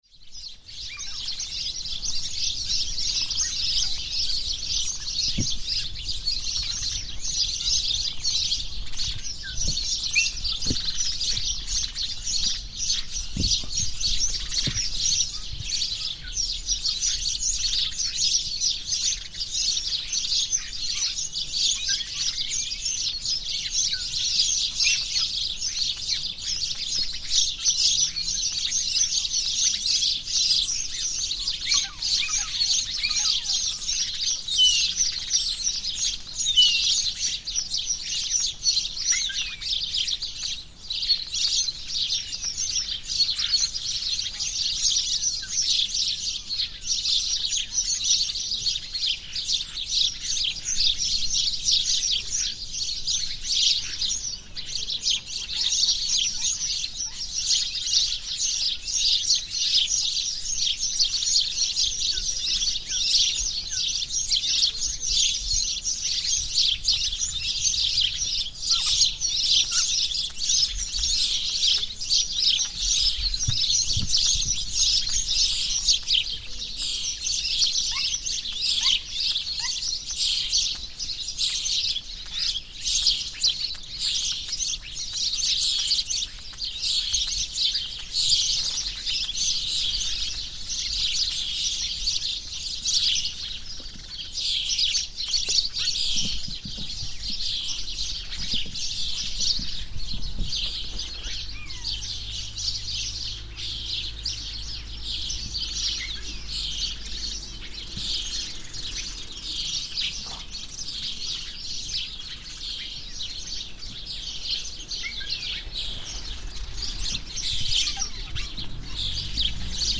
A carpet of birdsong
In the gardens on Ponta do Sossego on São Miguel in the Azores, two imposing trees frame the entrance.
In the thick canopy of these trees, dozens and dozens of birds of all kinds of species sing frantically, creating a carpet of birdsong that greets visitors entering the garden on this quiet morning.